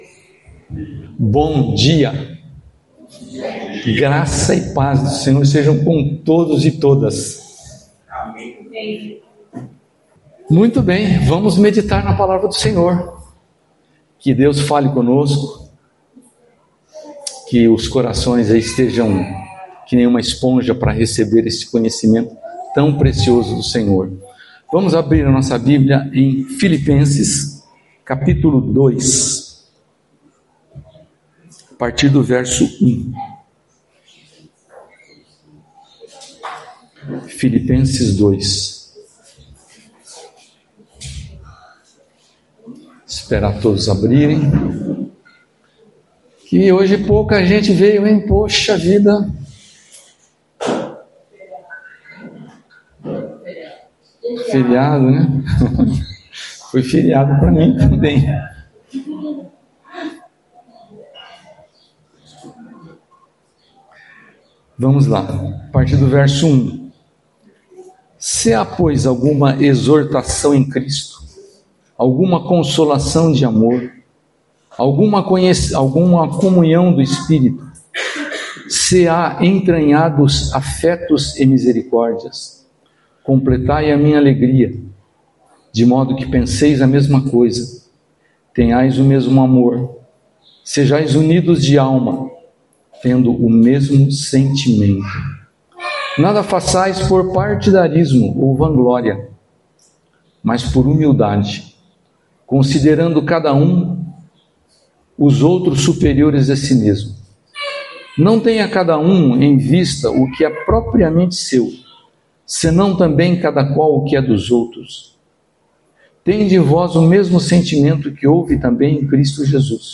Palavras ministradas